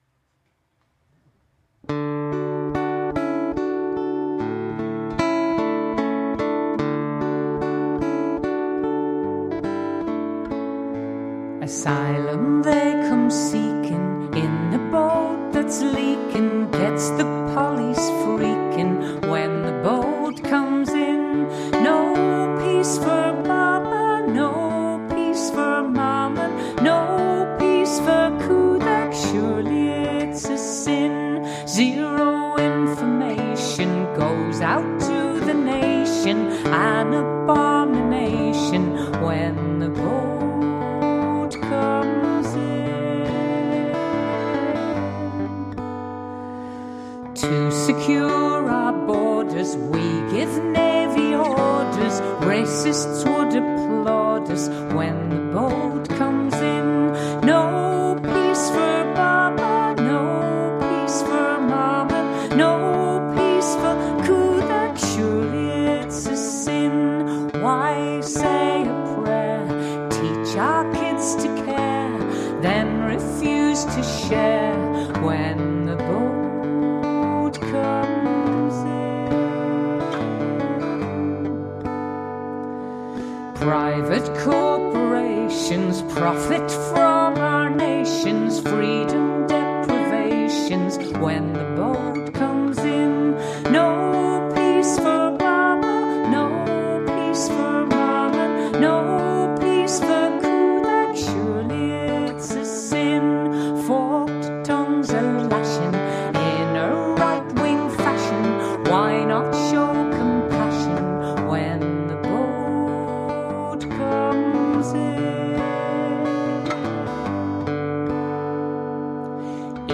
strings
Trad – Northumberland